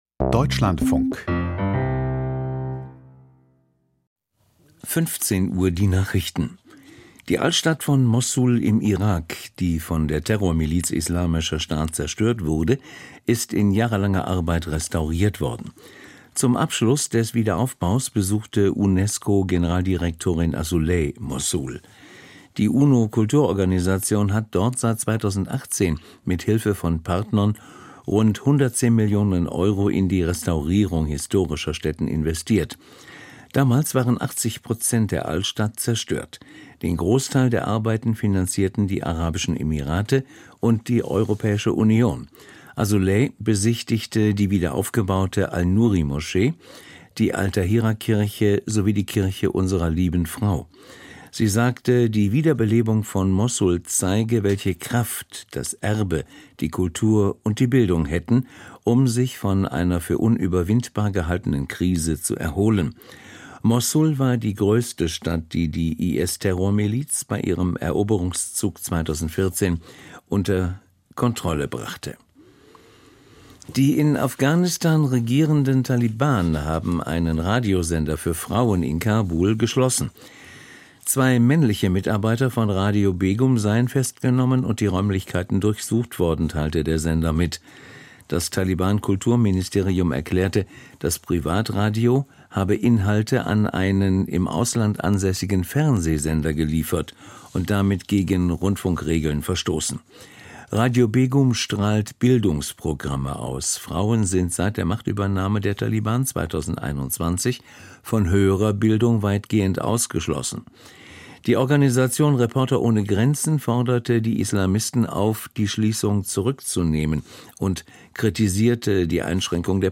Die Deutschlandfunk-Nachrichten vom 05.02.2025, 15:00 Uhr